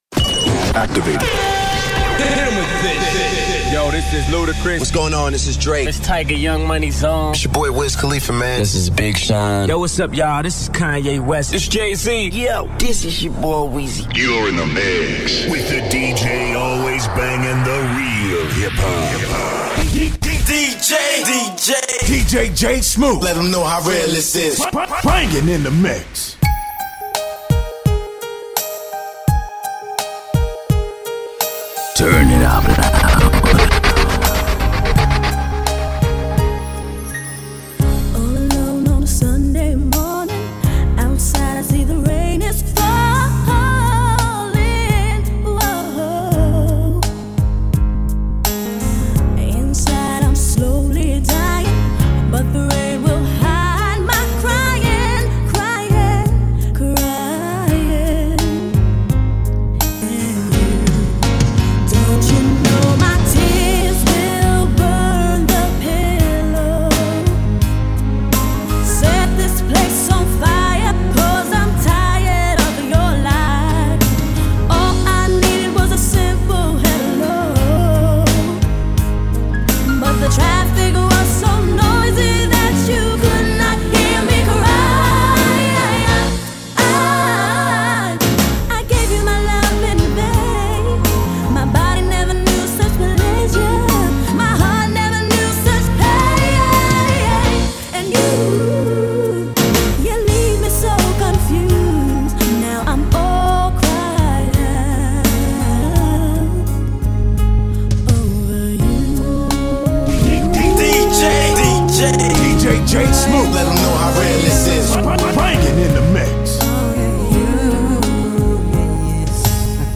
Old School Hip-Hop
Mix of Hip Hop from the year 1997